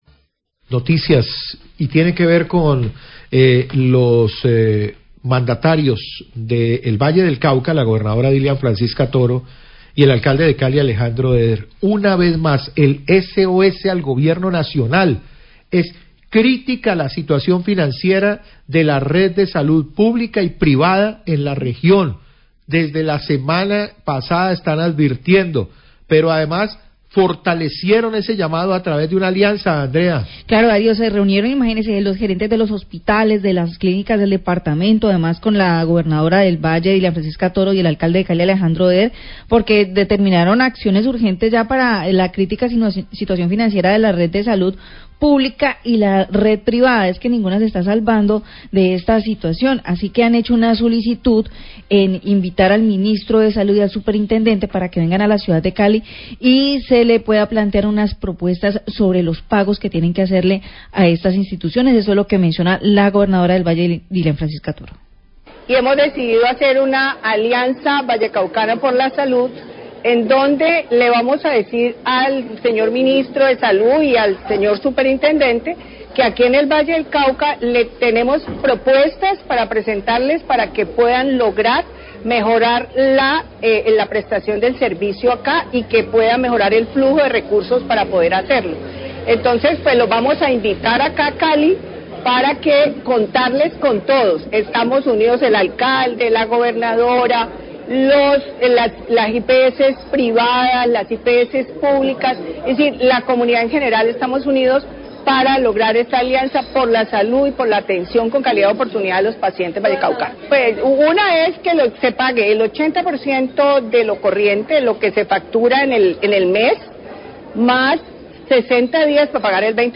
Radio
El alcalde de Cali, Alejandro Eder, habla de la falta de pagos que ponen en peligro la prestación del servicio de salud.